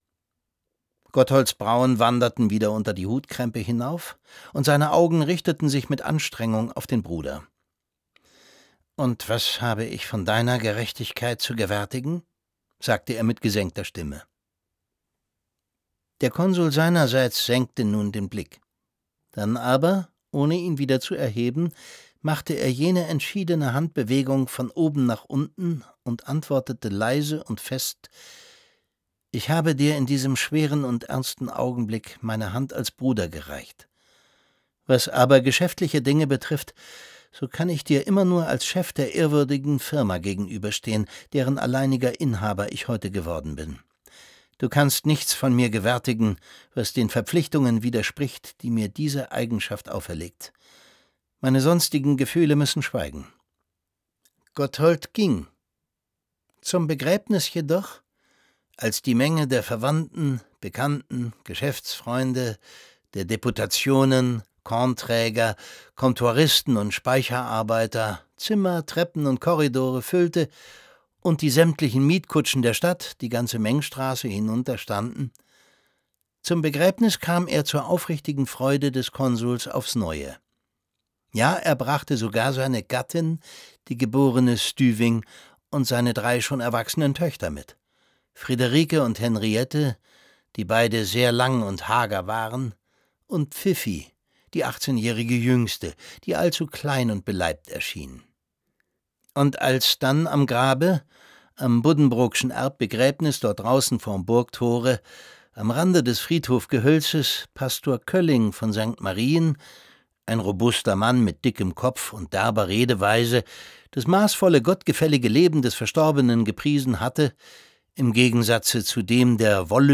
Nun erfährt er von seinem Stiefbruder Jean, dass er vom verstorbenen Vater keine Erbschaft zu erwarten hat. Es liest Thomas Sarbacher.